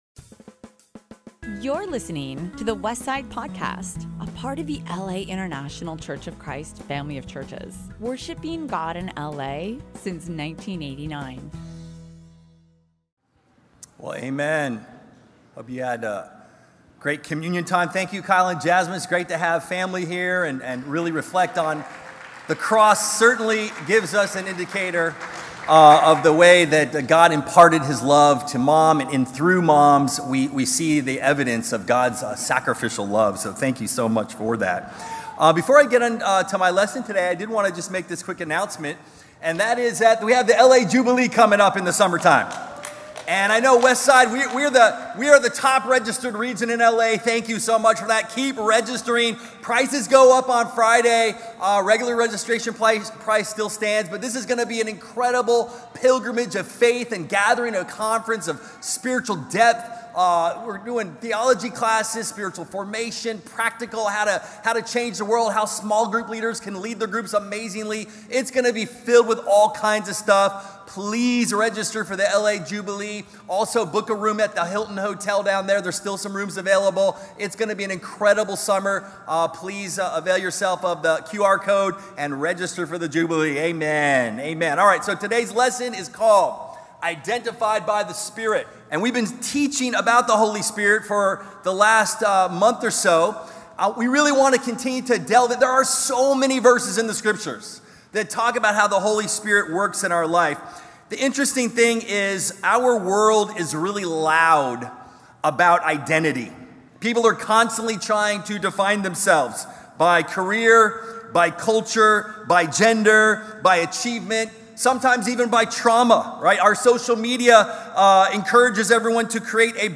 Past Sermons | The Westside Church - Los Angeles, CA